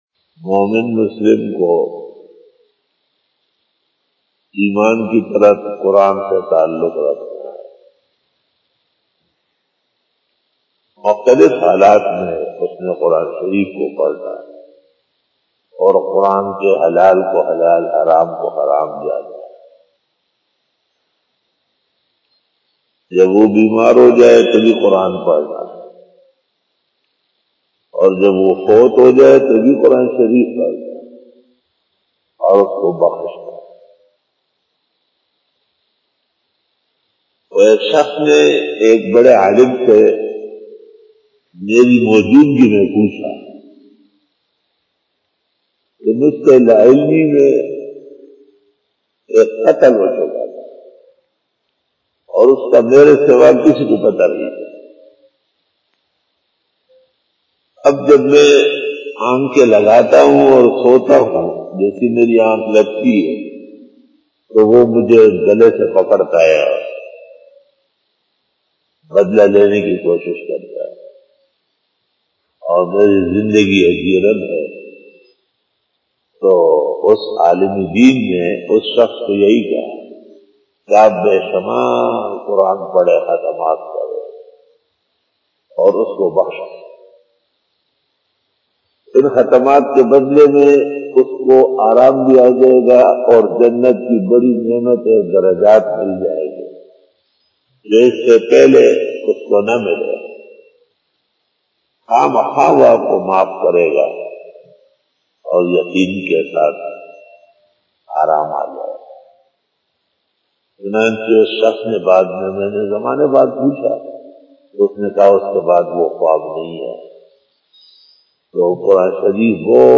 After Namaz Bayan
Fajar bayan